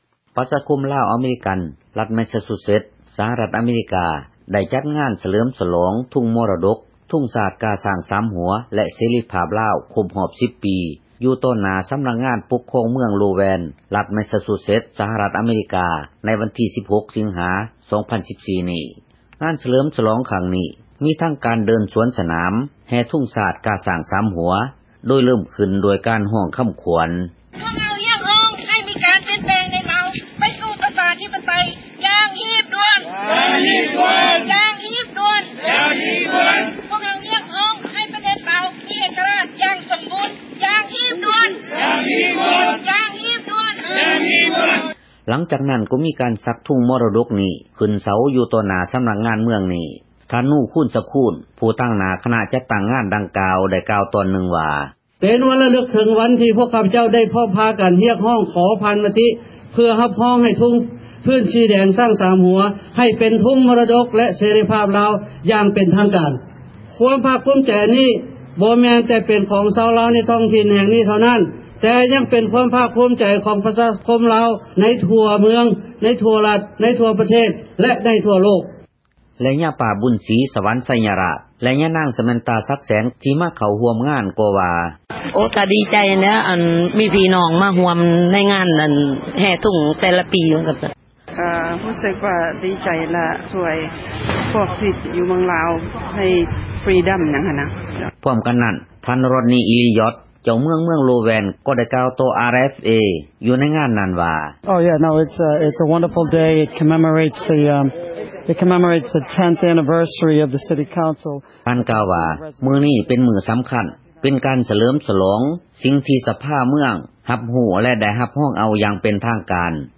Citizen journalist